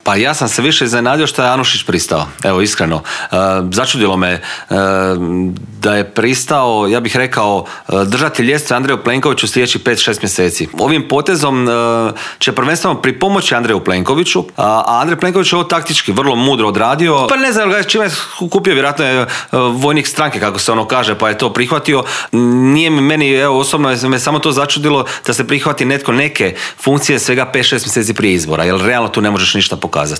Tim povodom u Intervjuu tjedna Media servisa ugostili smo predsjednika Hrvatskih suverenista Marijana Pavličeka, koji je prokomentirao još neke aktualnosti u zemlji od izbora novog ministra obrane do obilježavanja Dana sjećanja na žrtvu Vukovara.